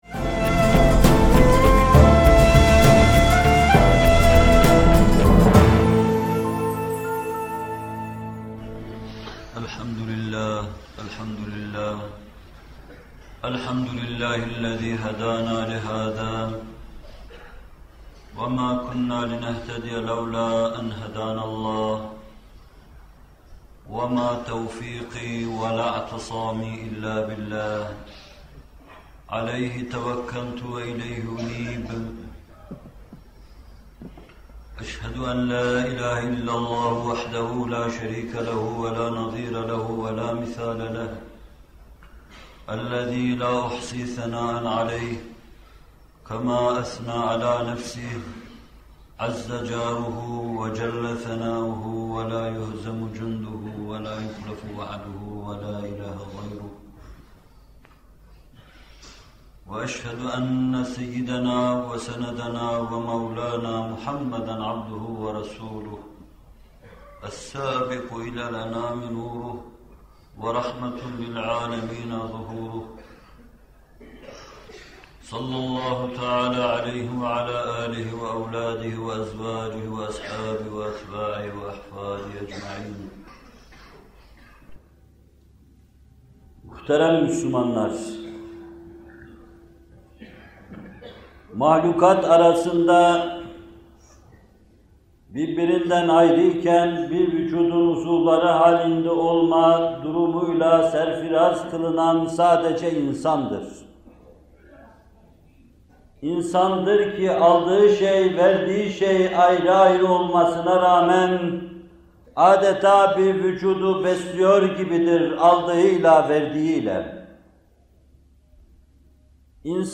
Zekat Vaazları – Zekât Hutbe -4- (23.Bölüm) - Fethullah Gülen Hocaefendi'nin Sohbetleri